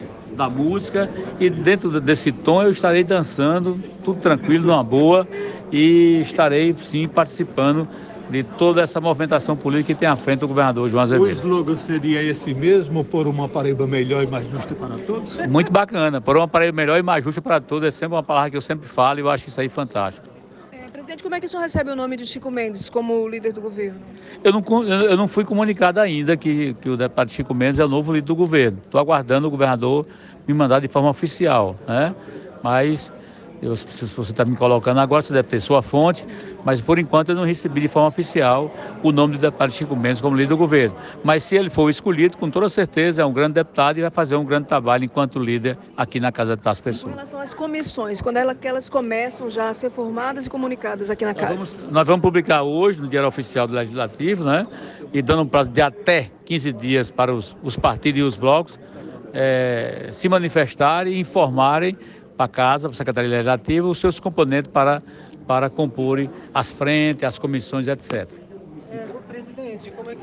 Os comentários do parlamentar foram registrados pelo programa Correio Debate, da 98 FM, de João Pessoa, nesta terça-feira (07/02).